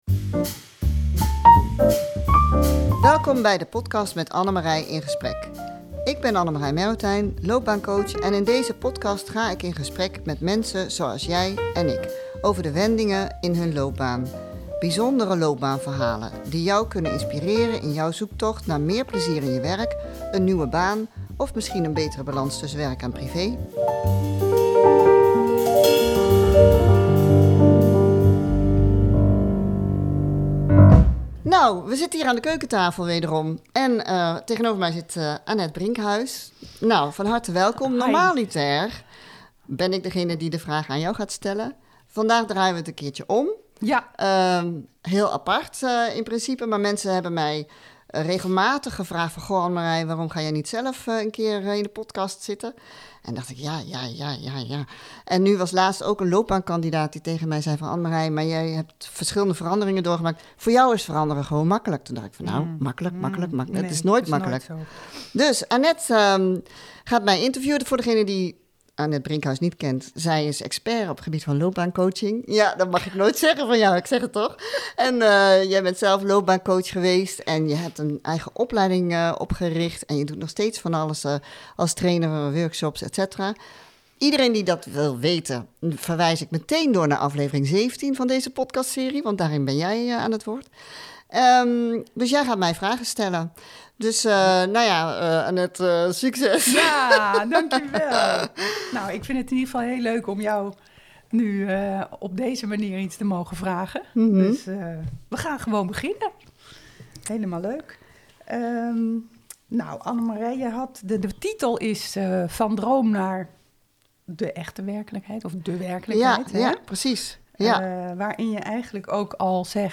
in deze podcast ga ik in gesprek met mensen zoals jij en ik, over de wendingen in hun loopbaan. Bijzondere loopbaanverhalen die jou kunnen inspireren in jouw zoektocht naar meer plezier in je werk, een nieuwe baan of een betere balans tussen werk en privé.